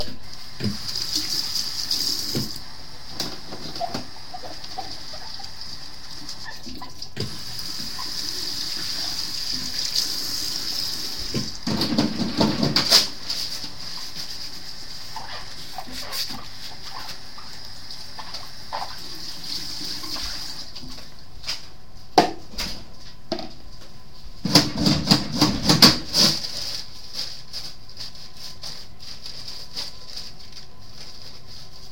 Field Recording #11
SOUND CLIP: Field Recording 11 LOCATION: Republic Hall bathroom SOUNDS HEARD: door shutting, sink, washing hands, soap dispenser, scrubbing plastic container, paper towel dispenser